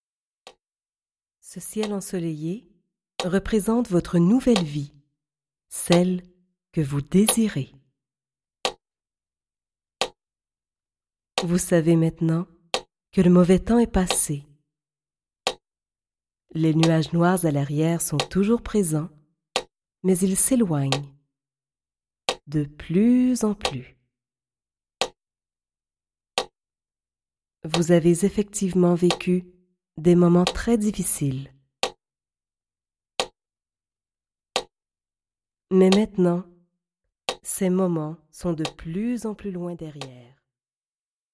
Cette technique, unique et efficace, vous guidera pas à pas à travers une séance de visualisation (imagerie mentale).
Grâce au rythme créé par le métronome, un codage vibratoire puissant synchronise les hémisphères du cerveau. À la fin de l’exercice, deux allégories (contes fantastiques) sont captées simultanément et individuellement par les nerfs auditifs de chaque oreille, et ce afin de contourner toutes les résistances.
Fond sonore : Métronome